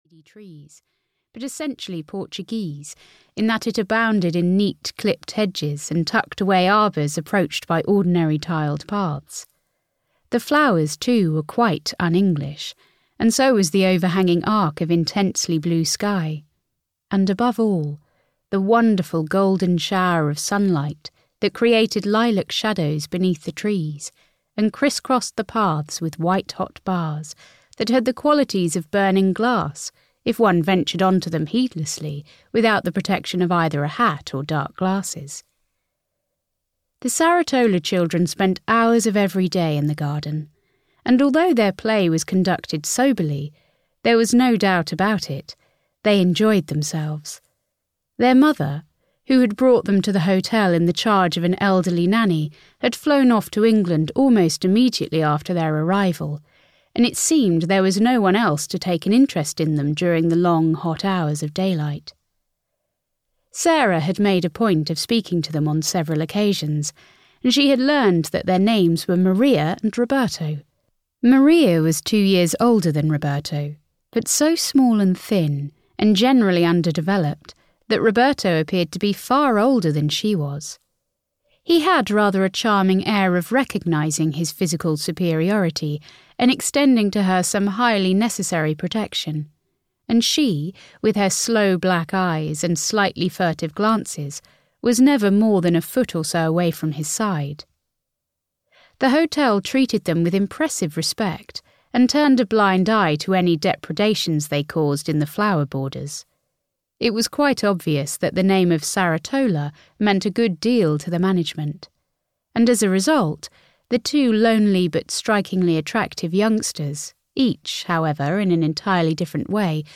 The Bay of Moonlight (EN) audiokniha
Ukázka z knihy